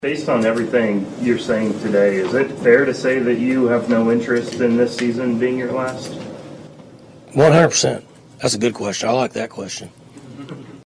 Oklahoma State head football coach Mike Gundy had an interesting press conference on Monday in Stillwater, as he continues to face major questions about his future.